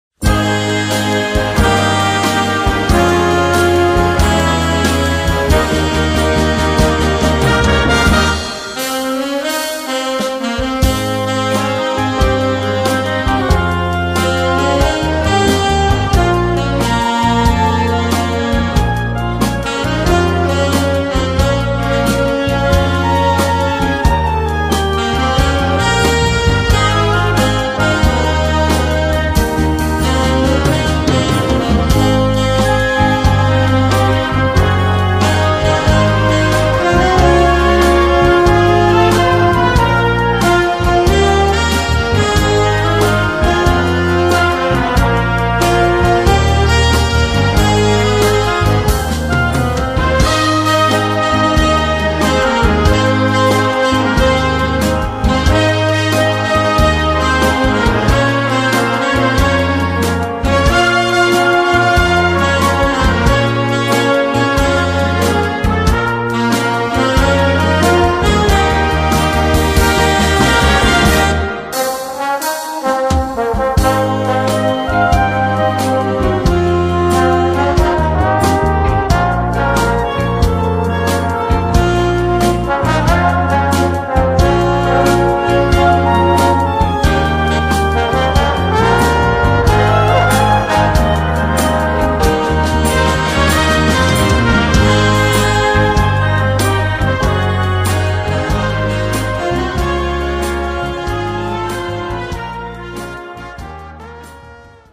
Voicing: Brass Band